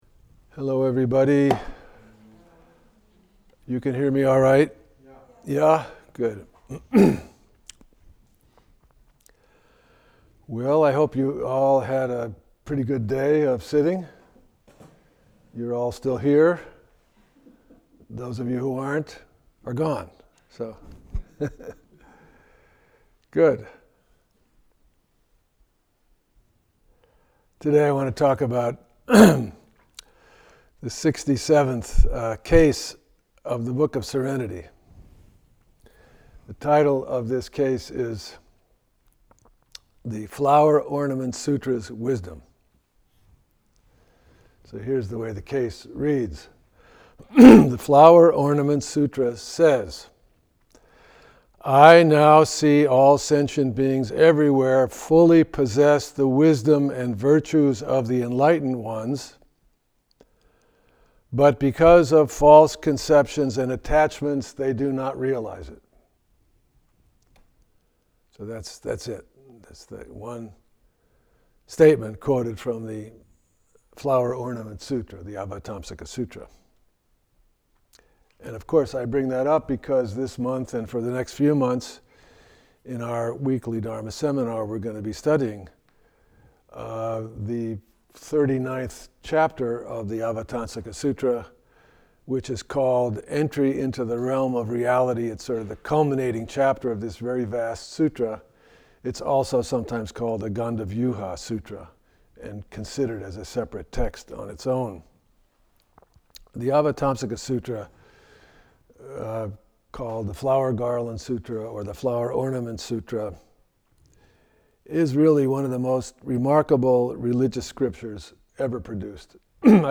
Gandavyuha Sutra (Avatamsaka Sutra Chapter 39) – Talk 2 – All Day Sitting – August 2025